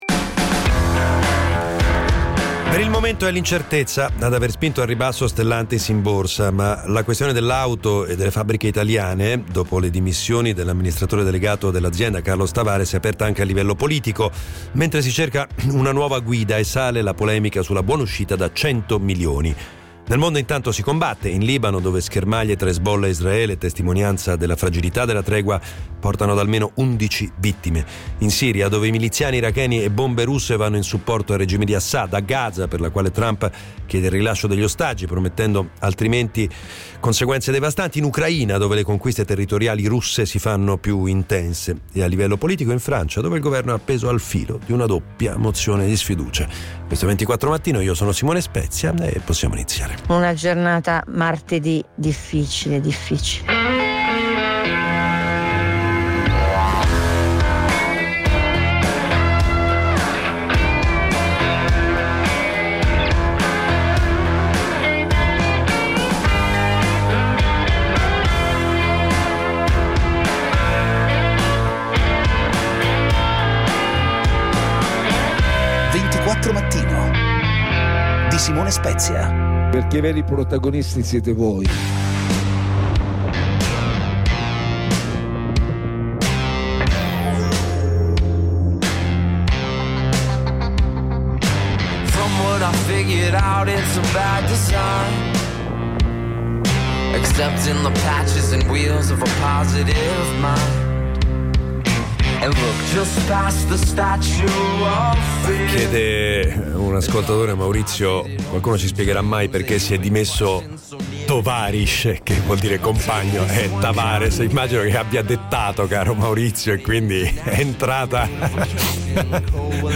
1 La giornata in 24 minuti del 3 dicembre Play Pause 6h ago Play Pause Riproduci in seguito Riproduci in seguito Liste Like Like aggiunto — L'apertura di giornata, con le notizie e le voci dei protagonisti tutto in meno di 30 minuti. A Roma, nella giornata di venerdì, un gruppo di alunni ha deciso di prendere possesso degli spazi scolastici per protestare contro il ministro Valditara, l'escalation in Palestina e l'alternanza scuola lavoro.